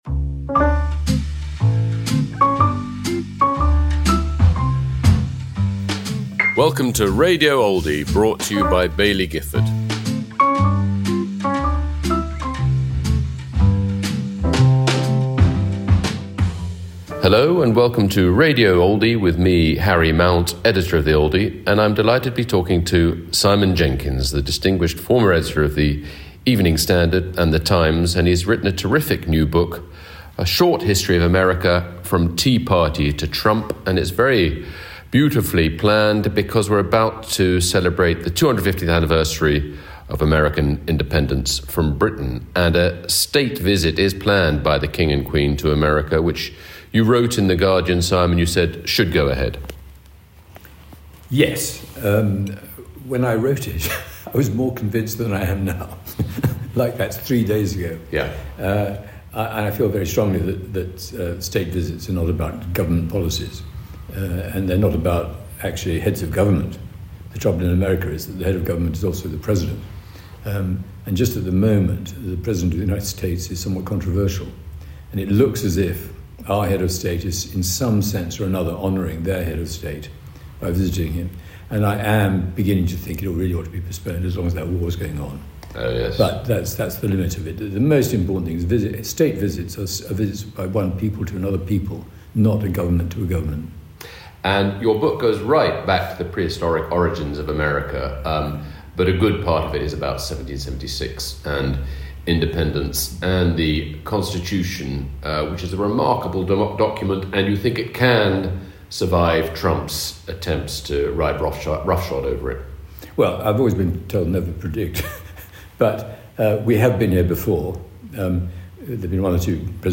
Simon Jenkins in conversation with Harry Mount